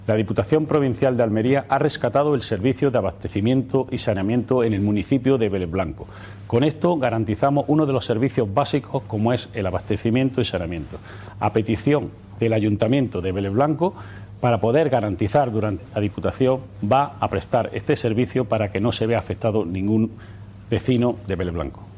Audio-diputado-Jose-Juan-Martinez-Pleno.mp3